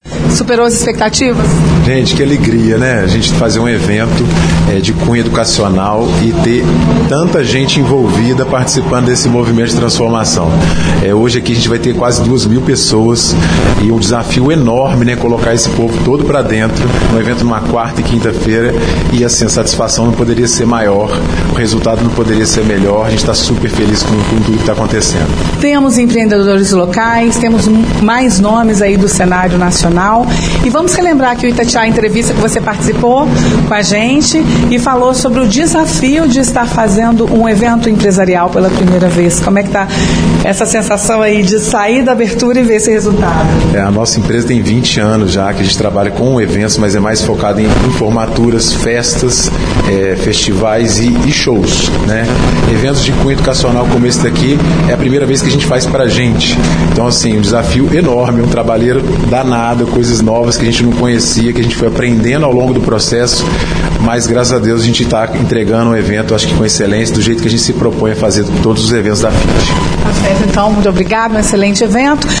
A reportagem da Itatiaia acompanhou a primeira tarde do evento e trouxe ao longo da programação as opiniões e avaliações de participantes.